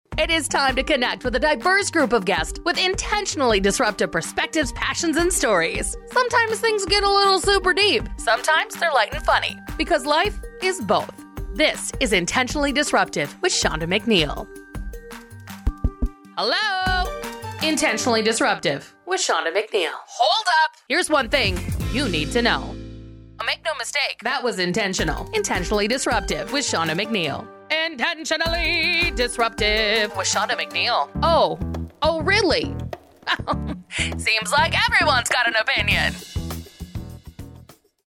American English Speaking Female Voiceover Artist & Broadcaster
American, Minnesotan/Midwestern
Middle Aged
Podcast Imaging Demo_Intentionally Disruptive.mp3